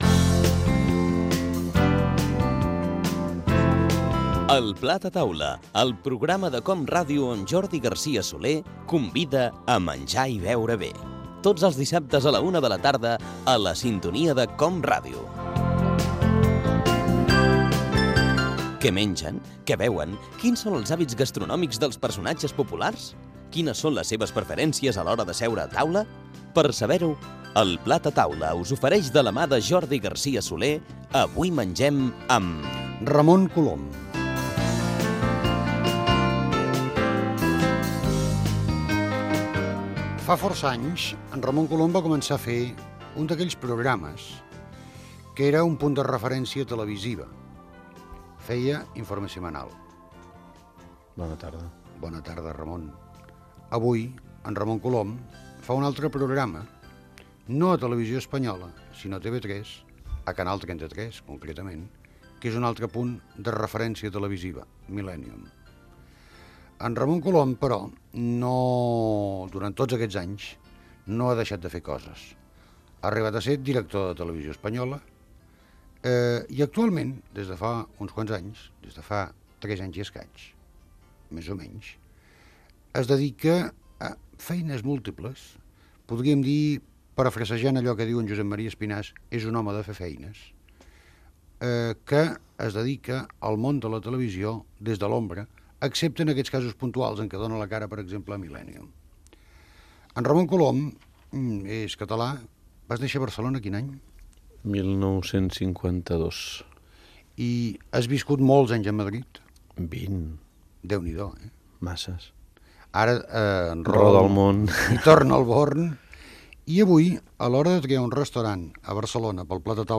e718d9e72017e83f102468a64c92e17fbd942441.mp3 Títol COM Ràdio Emissora COM Ràdio Barcelona Cadena COM Ràdio Titularitat Pública nacional Nom programa El plat a taula Descripció Careta del programa, presentació i fragment d'una entrevista al periodista Ramon Colom.